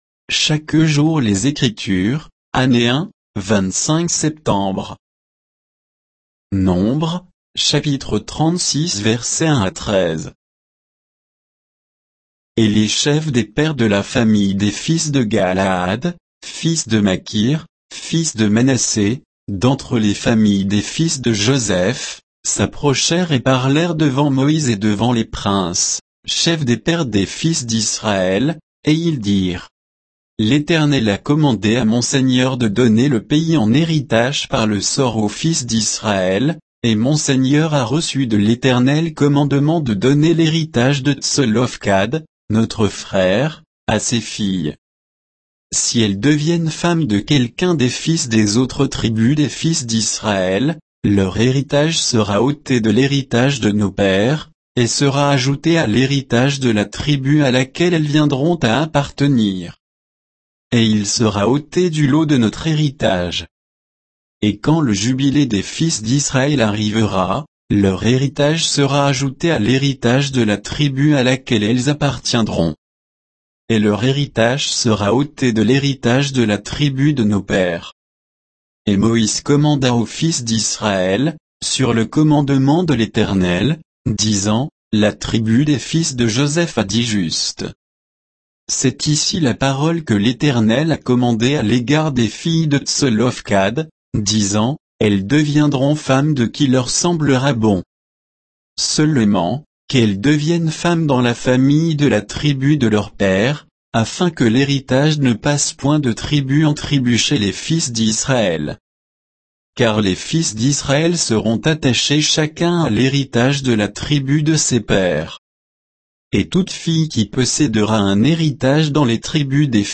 Méditation quoditienne de Chaque jour les Écritures sur Nombres 36, 1 à 13